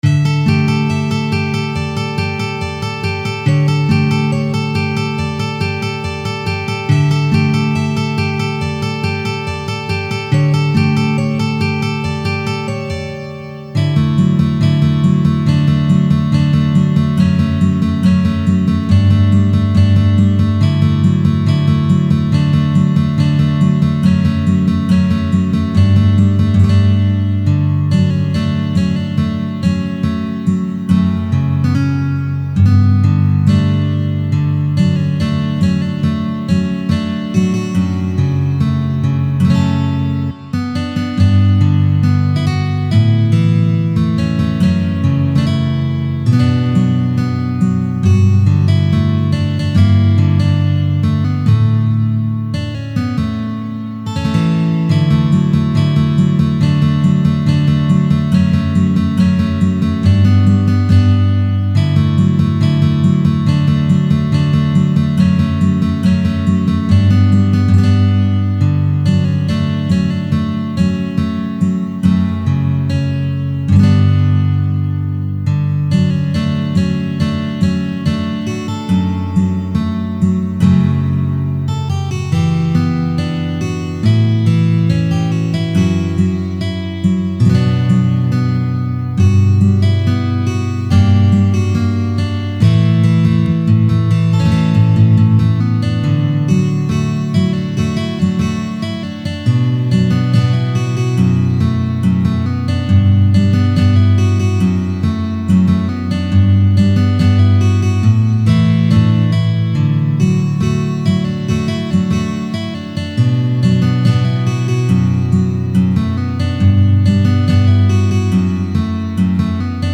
2品C调指法
指弹谱